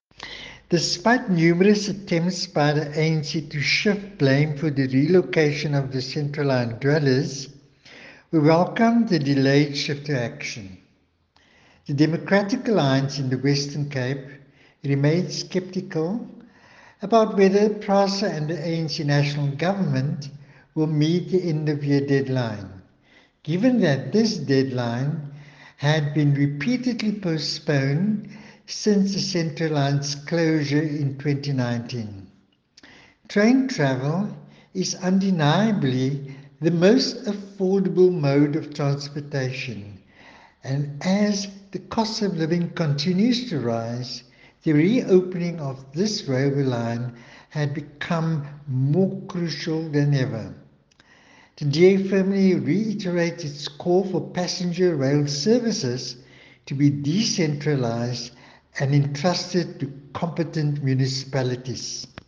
attached an audio clip by MPP Derrick America